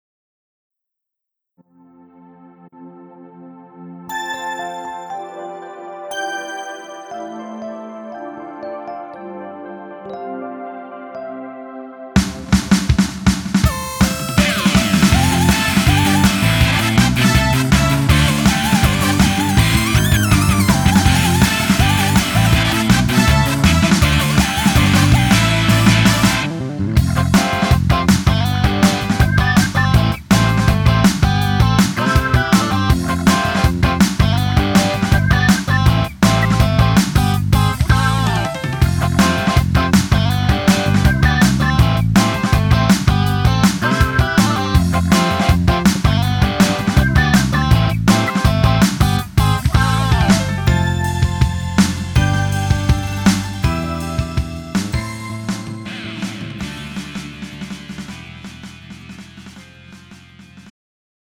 음정 -1키 3:42
장르 가요 구분 Pro MR